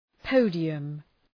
{‘pəʋdıəm}